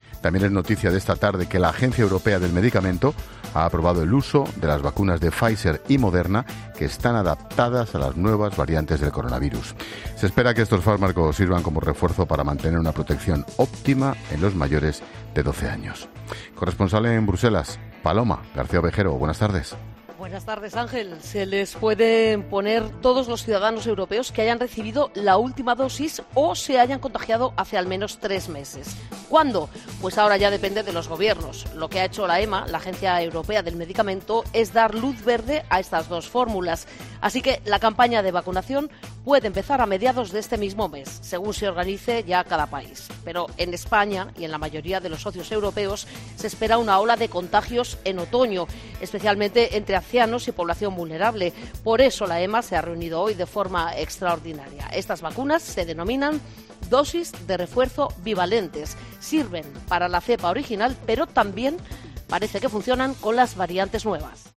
Te da más detalles la corresponsal de COPE en Bruselas, Paloma García Ovejero